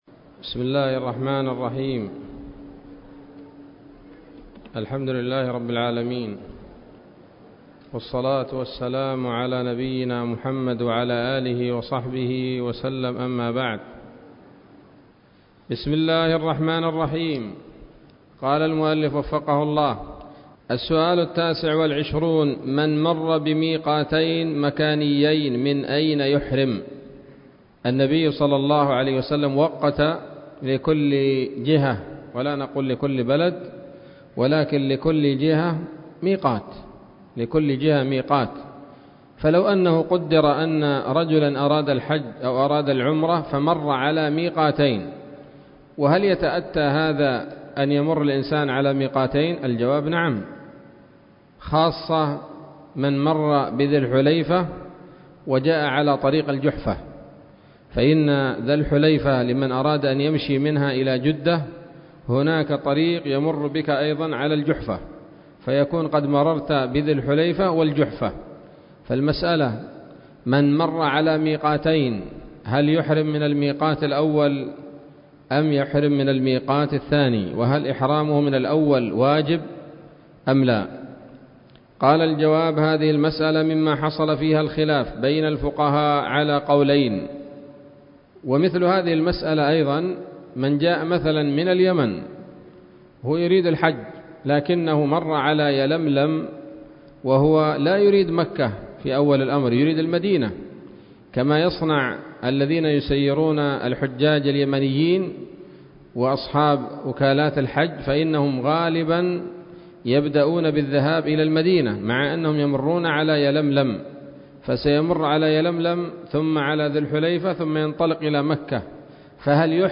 الدرس الرابع والعشرون من شرح القول الأنيق في حج بيت الله العتيق